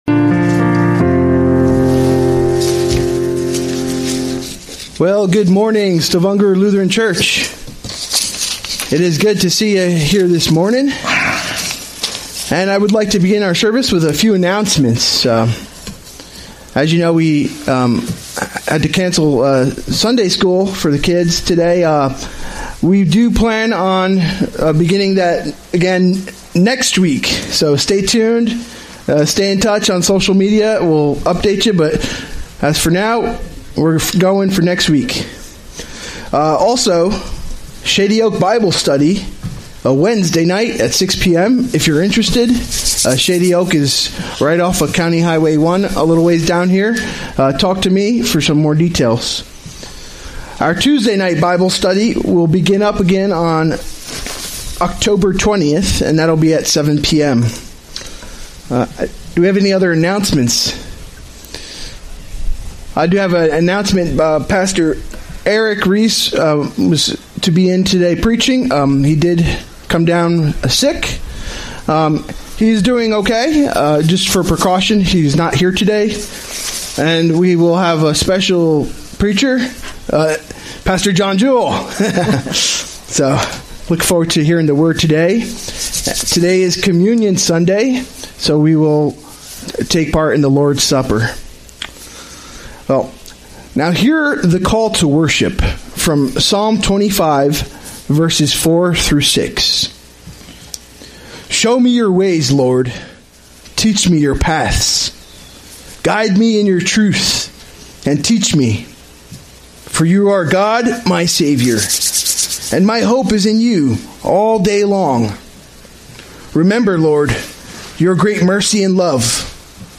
A message from the series "Sunday Worship."